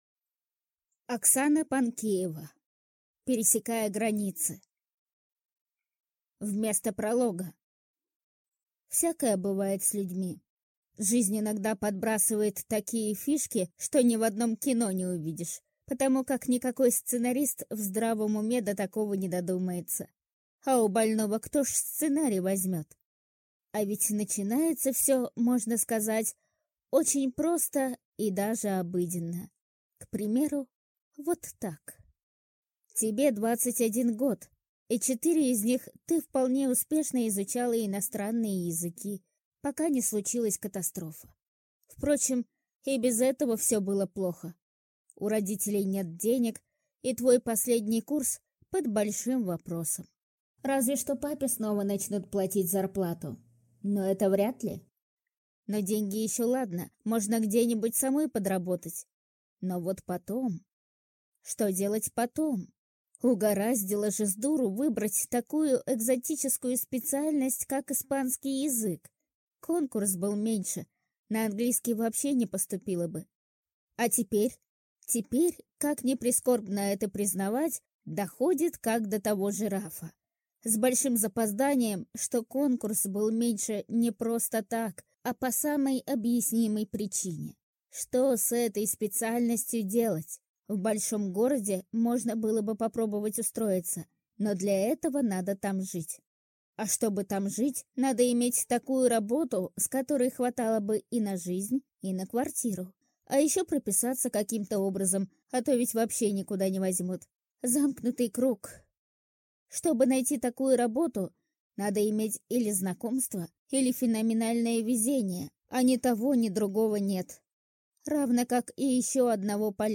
Аудиокнига Пересекая границы | Библиотека аудиокниг